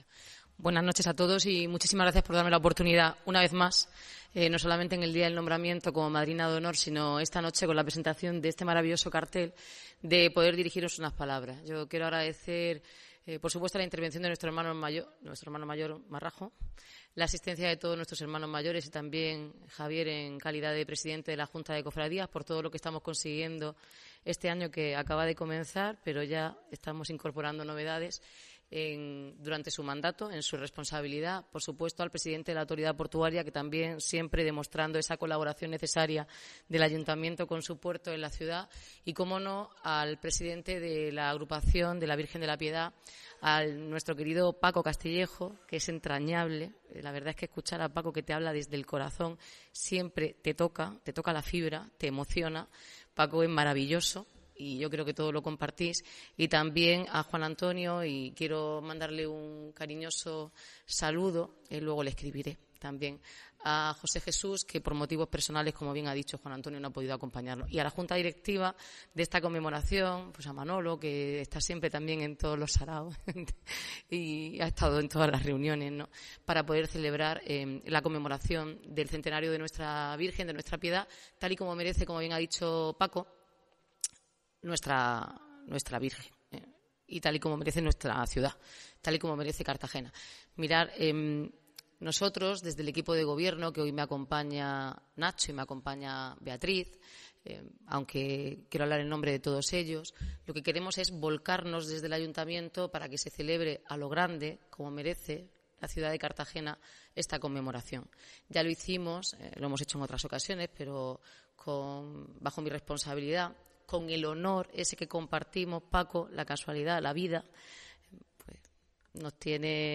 Enlace a Declaraciones de la alcaldesa, Noelia Arroyo, en la presentación del cartel del centenario de La Piedad